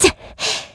Ripine-Vox_Landing_kr.wav